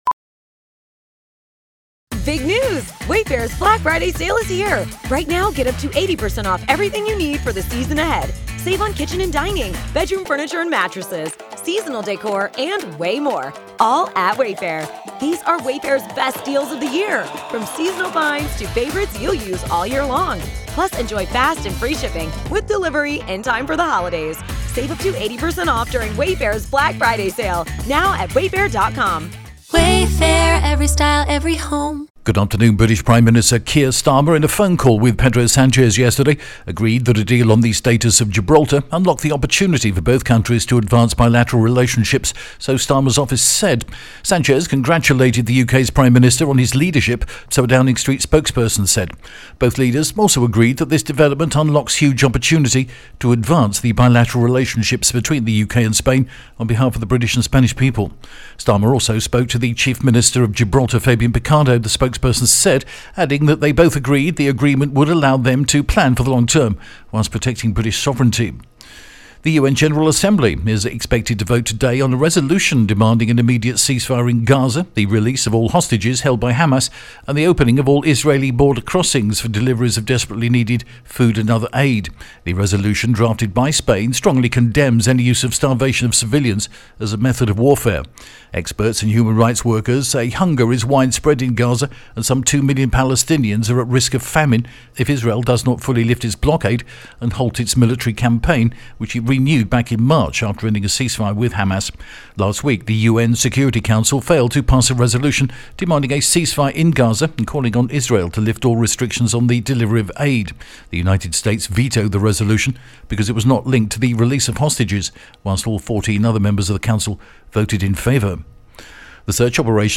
The latest Spanish news headlines in English: June 12th 2025